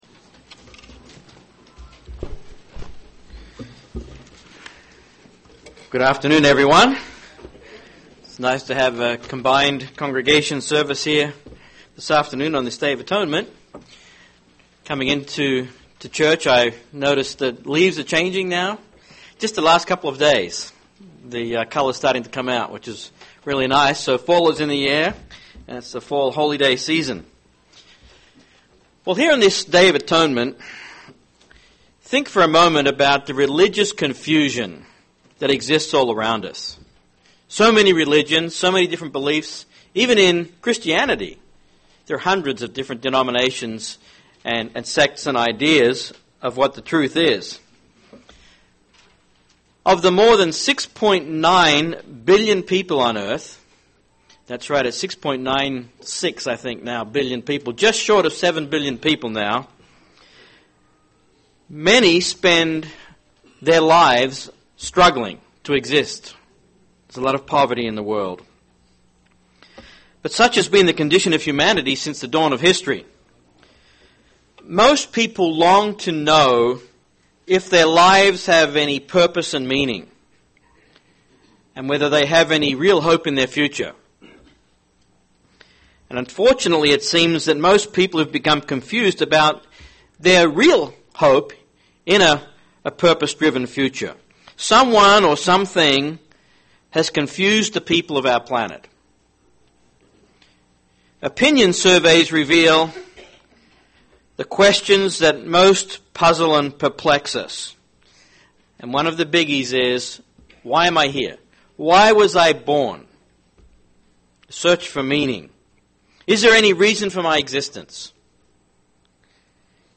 Atonement sermon